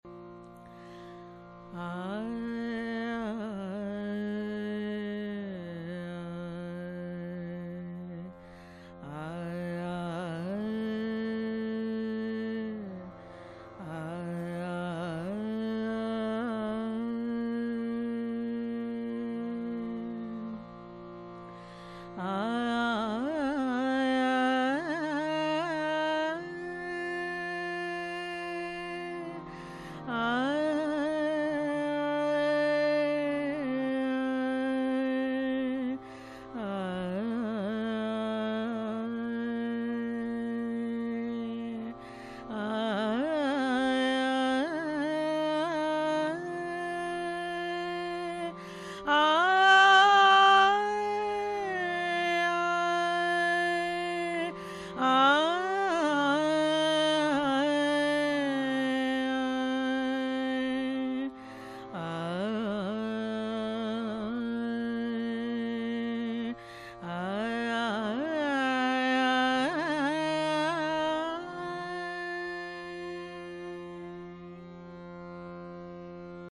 Jatti: Sharav Sampooar Thaat: Khamaj. Gandhar is absent in aroh. Use of both nishad, rest all swars are sudh.
Aroh: ਸ ਰ ਮ ਪ, ਧ ਨੁ ਪ, ਮ ਪ ਨ ਸ  Sa Re Ma Pa, Dha Ni(k) Pa, Ma Pa Ni Sa
Avroh:  ਨੁ ਪ, ਧ ਮ ਗ ਰ, ਸ ਨ੍  Sa Ni(k) Pa, Dha Ma Ga Re, Sa Ni(mandar) Sa
Vadi: Pa
Samvadi: Re
Vocal:
vadhans_vocal.mp3